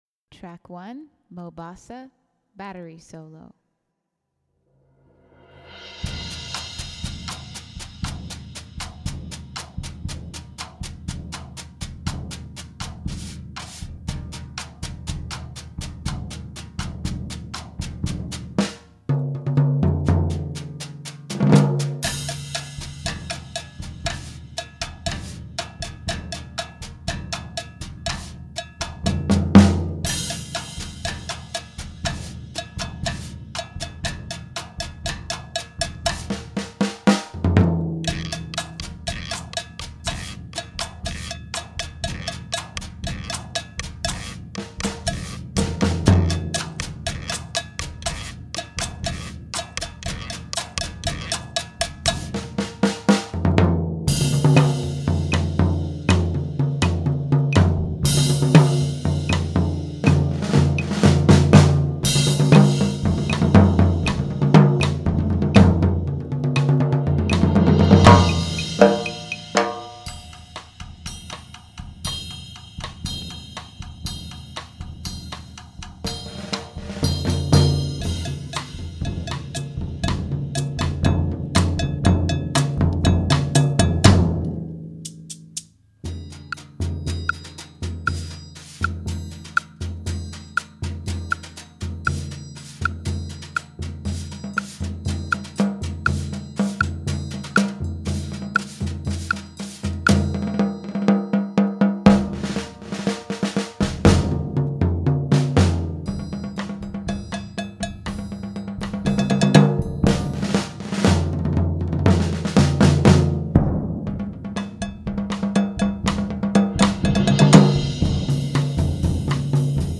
Battery Solo